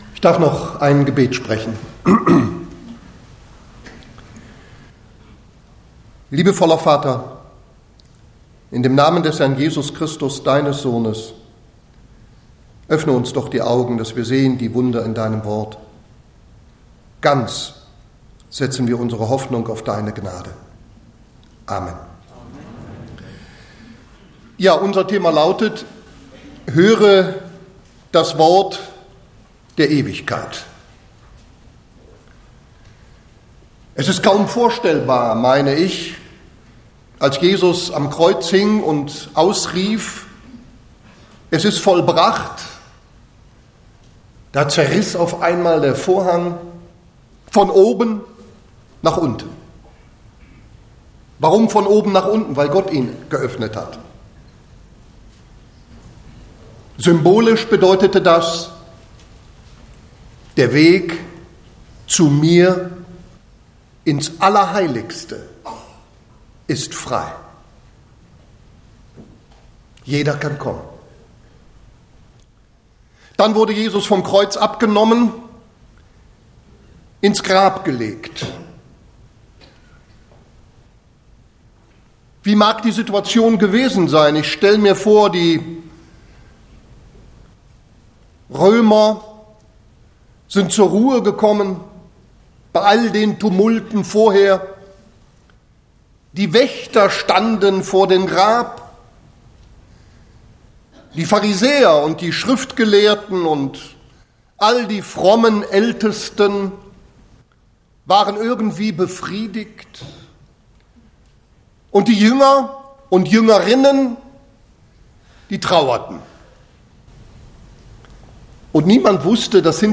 Botschaft